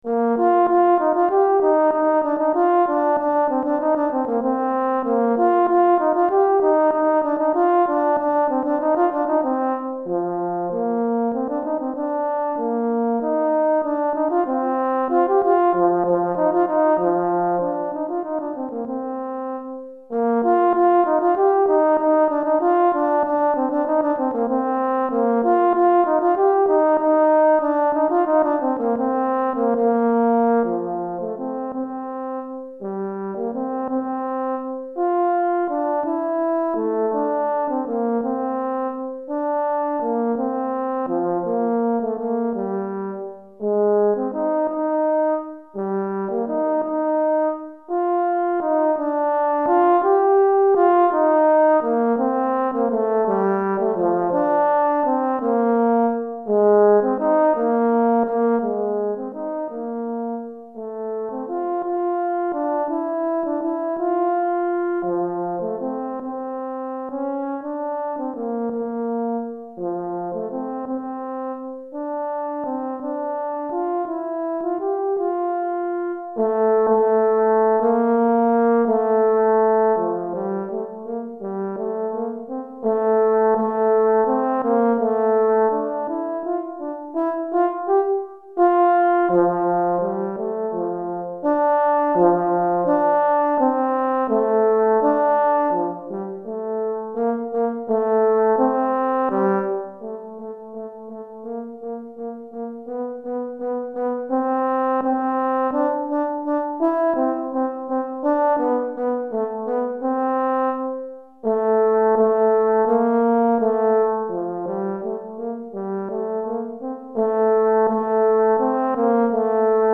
Cor en Fa Solo